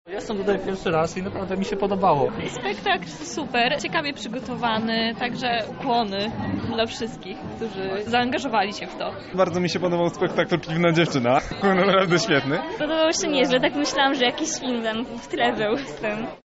O wrażeniach opowiedzieli widzowie.